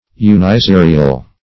Uniserial \U`ni*se"ri*al\, a.